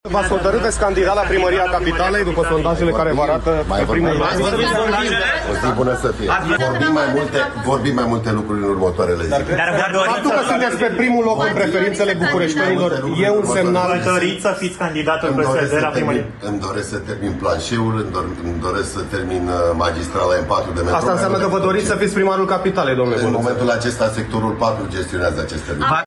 Daniel Băluță, primarul Sectorului 4: „Vorbim mai multe lucruri în următoarele zile”